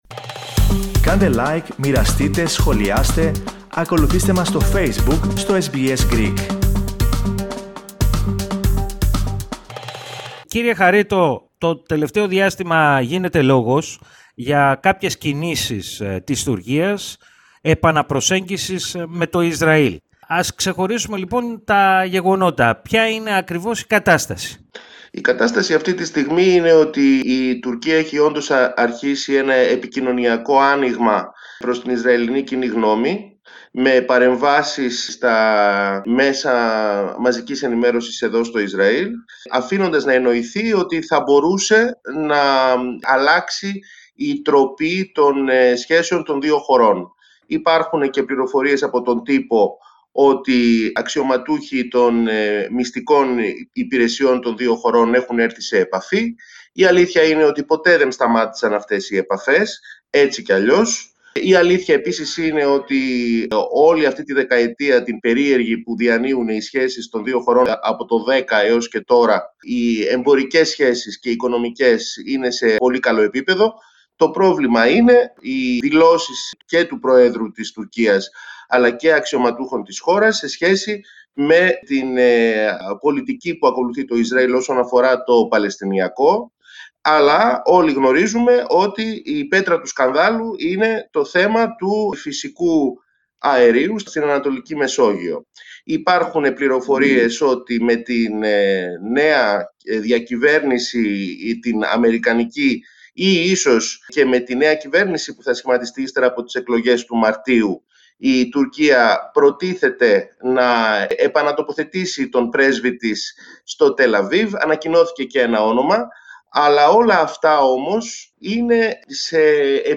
στη συνέντευξη που παραχώρησε στο Ελληνικό Πρόγραμμα της ραδιοφωνίας SBS